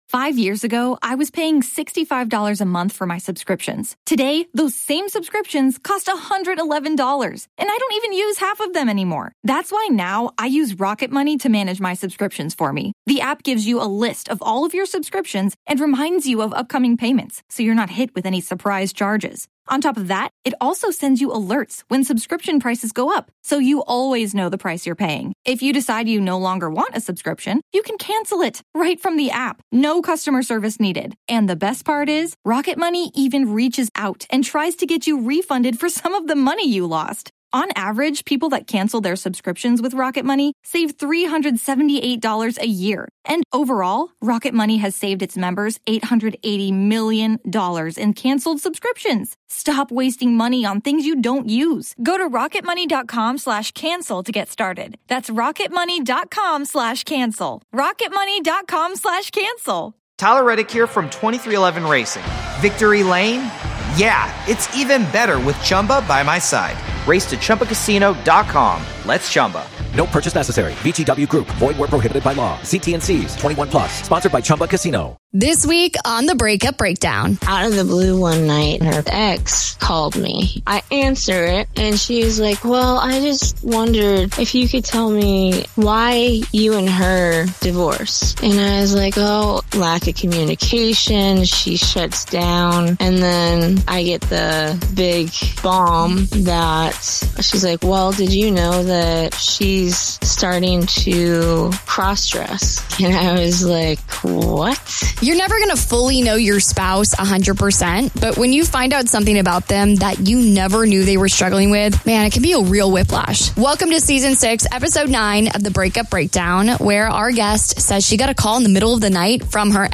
When this week's interviewee said she felt her husband pulling away, she thought it was just because they were growing apart. It wasn't until after the divorce that she found out the distance between them ran much deeper than personality differences.